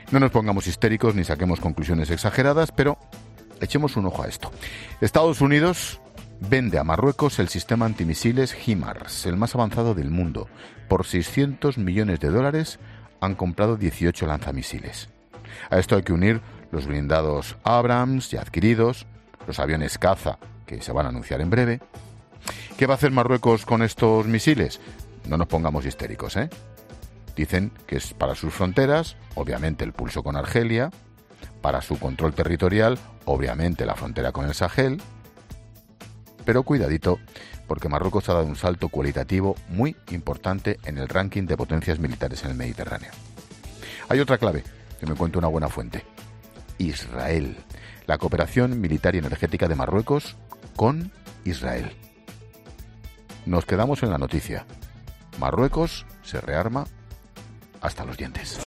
Eso sí, subrayando en su monólogo que no pretende ser “amarillista”.